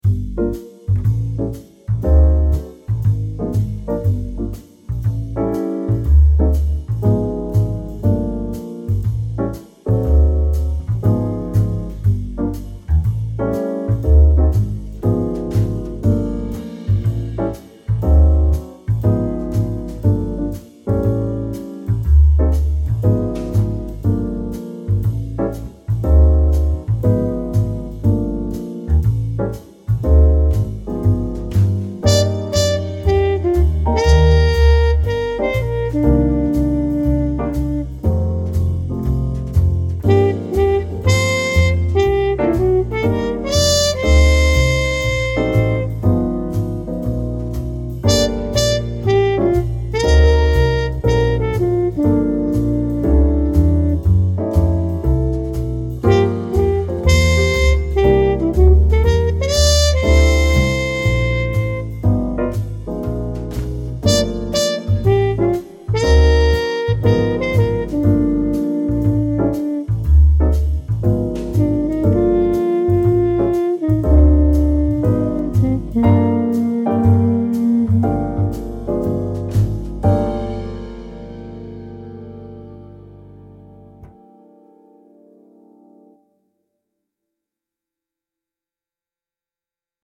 warm big band jazz standard style with muted trumpet and smooth bass